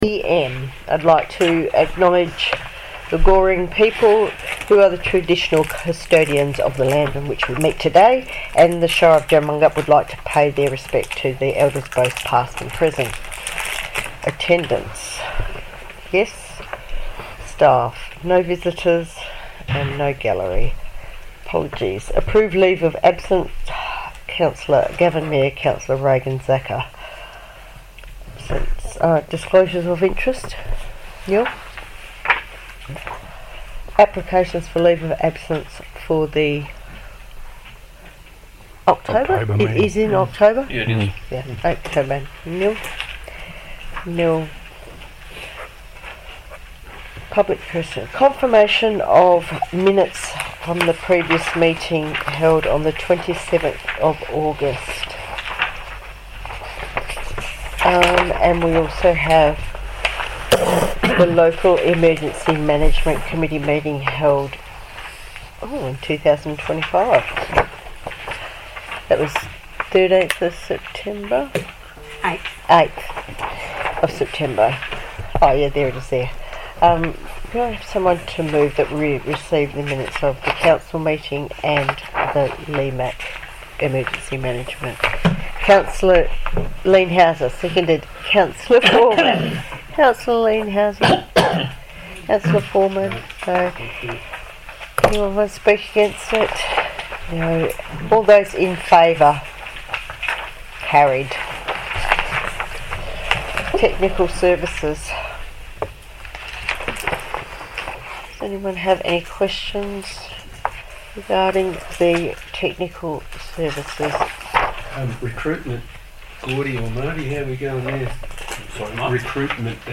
Ordinary Council Meeting - 1 October 2025 - Recording (14.39 MB)
ordinary-council-meeting-1-october-2025-recording.mp3